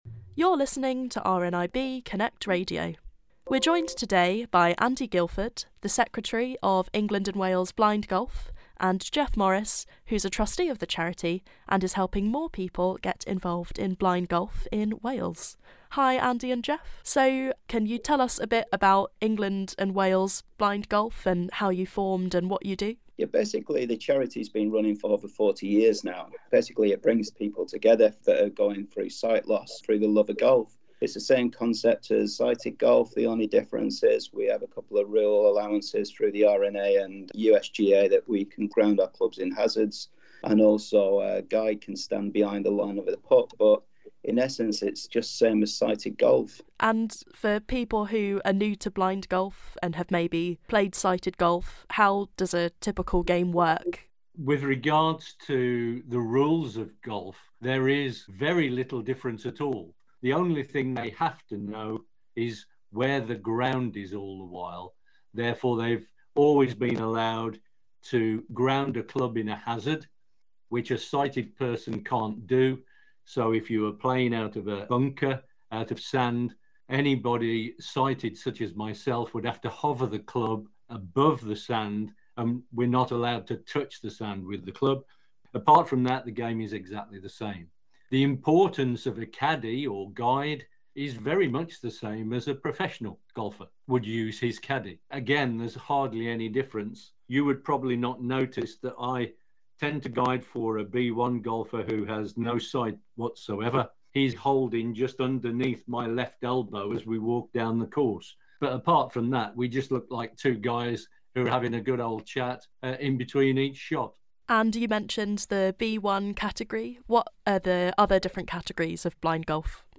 Download - S2 Ep656: Chat With Neil Fachie & Matthew Rotherham at Paralympics GB House | Podbean